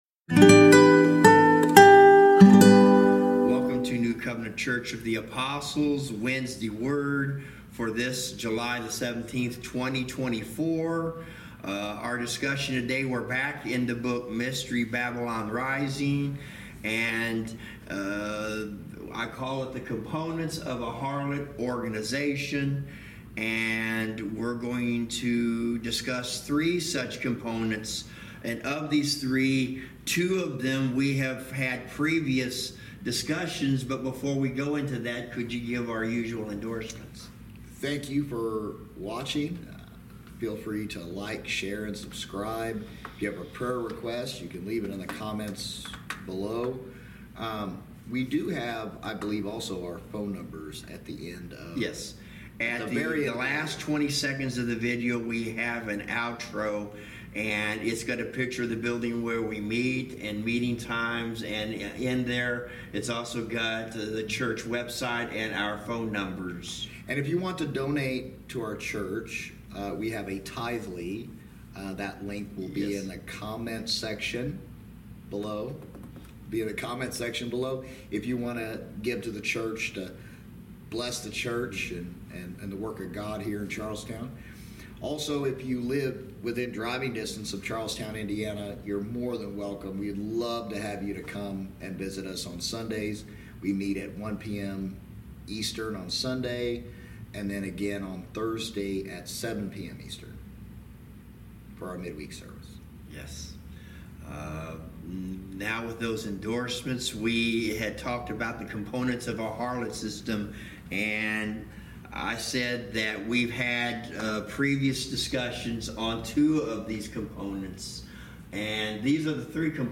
Service Type: Wednesday Word Bible Study